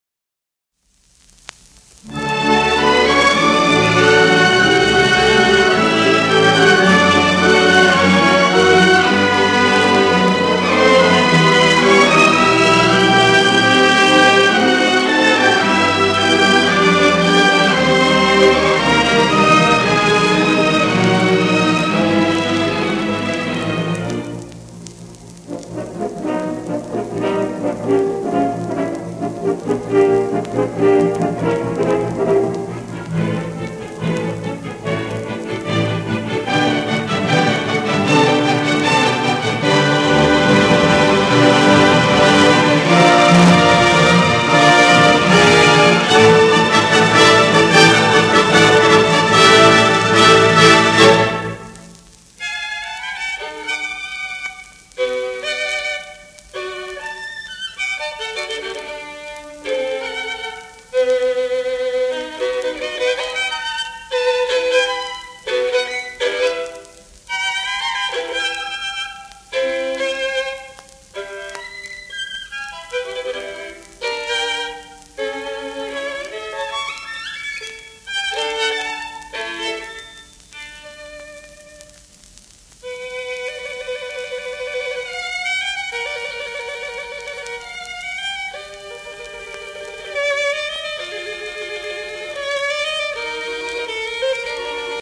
1936年録音